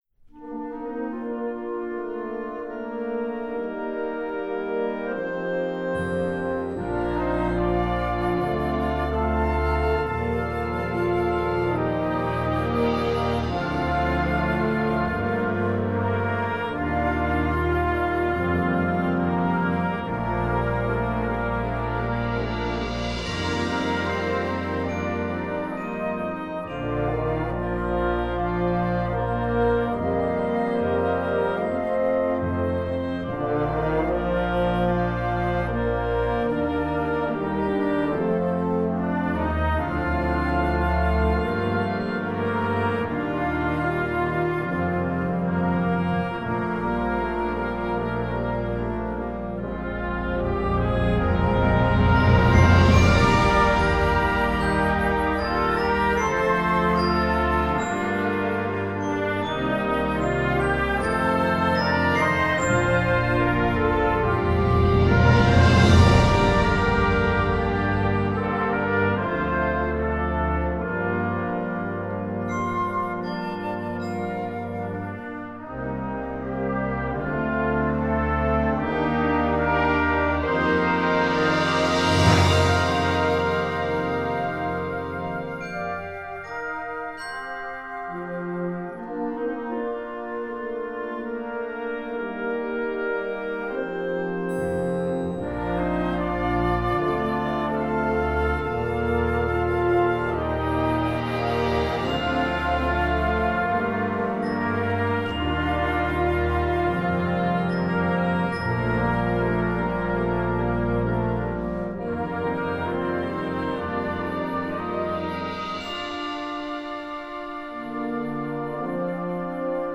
Gattung: Konzertwerk für flexibles Jugendblasorchester
Besetzung: Blasorchester